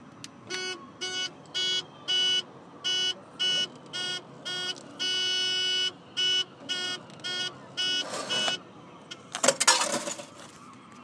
Car parking meter noise boo